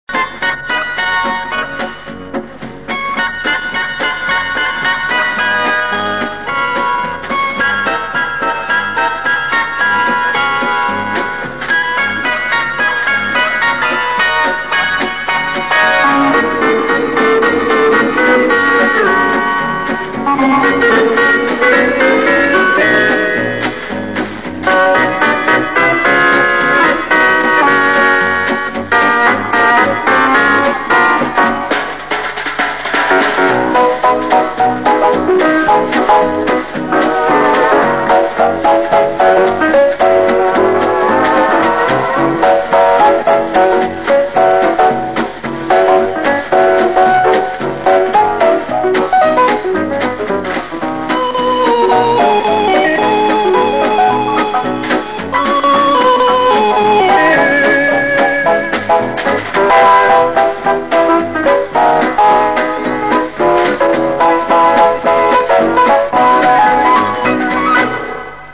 Твист и фокстрот. Две инструменталки 60-х годов для опознания
fokstrot-na-elektromuzyikalnyih-instrumentah-(zapis-60-h-godov).mp3